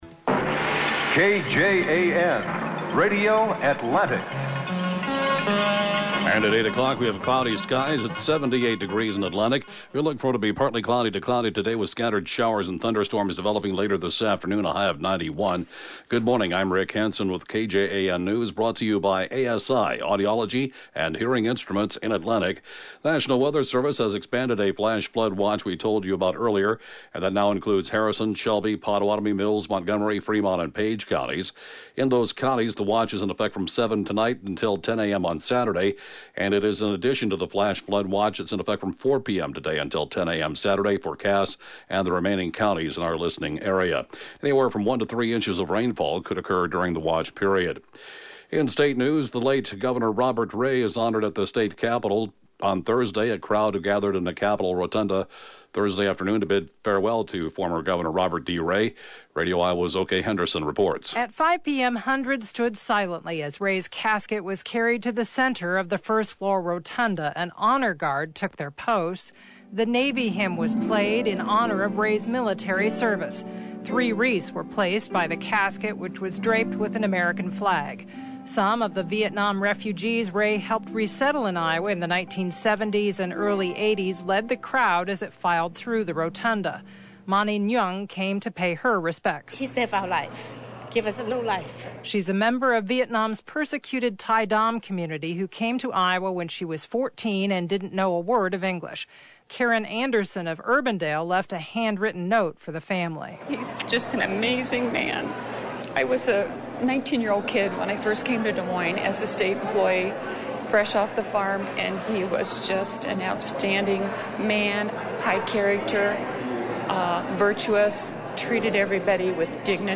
(Podcast) KJAN 8-a.m. News, 7/13/2018